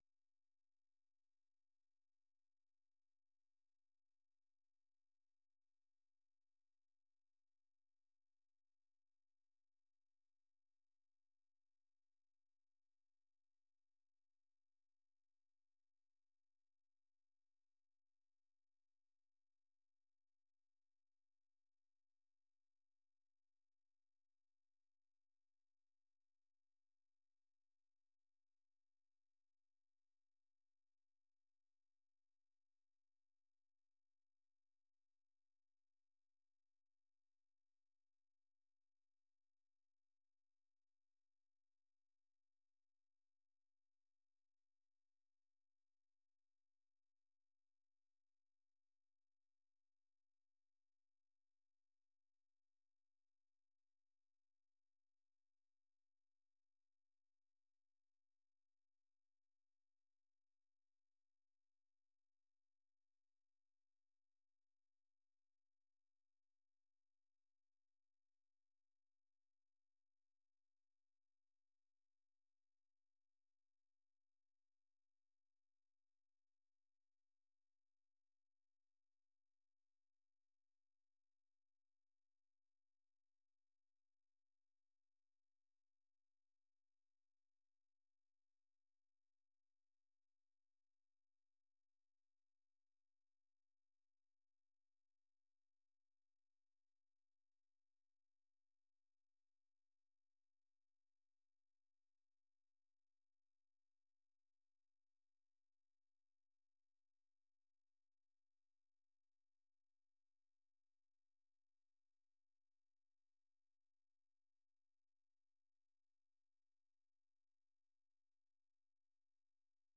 생방송 여기는 워싱턴입니다 아침
세계 뉴스와 함께 미국의 모든 것을 소개하는 '생방송 여기는 워싱턴입니다', 아침 방송입니다.